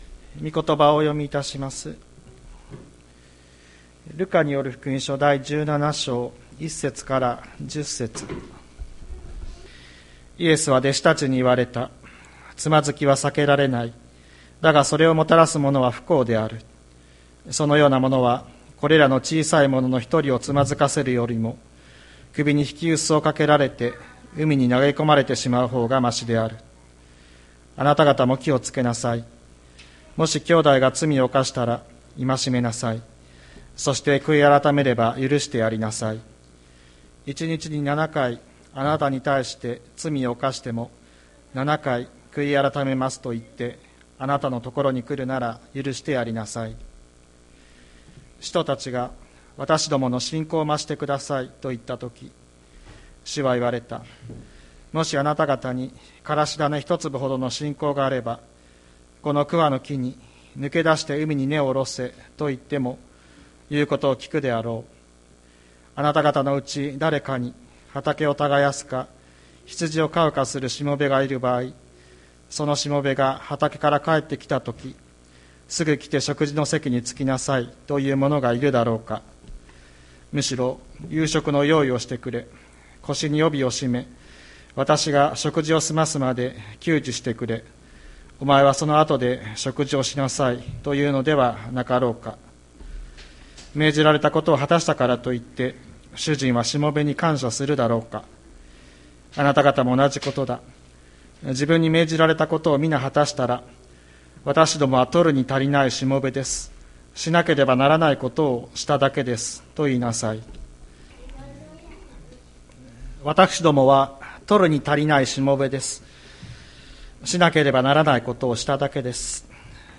2023年01月08日朝の礼拝「わたしたちはキリストのしもべ」吹田市千里山のキリスト教会